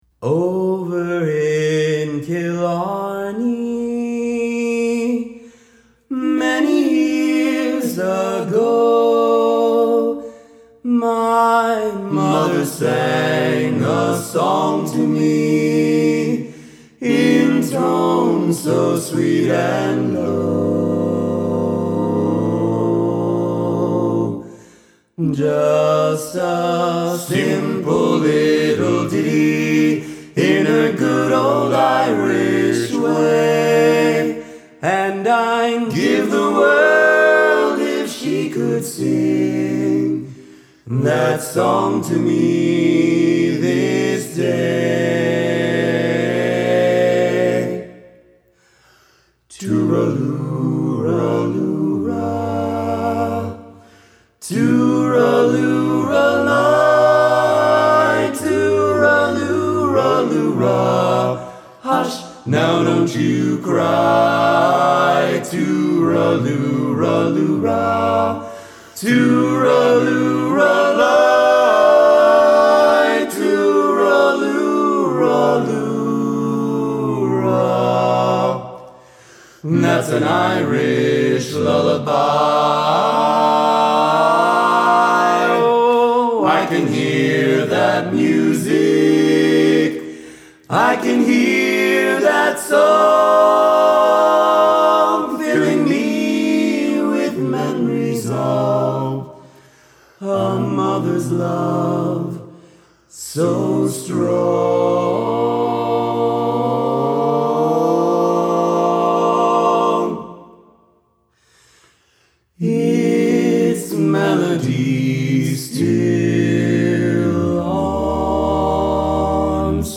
Brooklyn's best-if-not-only active barbershop quartet.